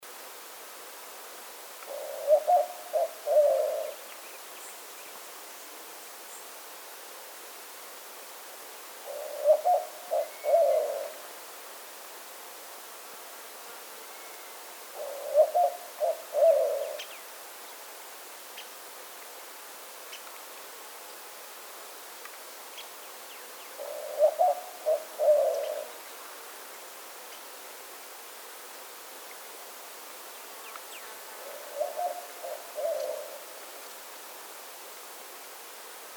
Genre: Columbidae.
White-winged Dove (Zenaida asiatica mearnsi)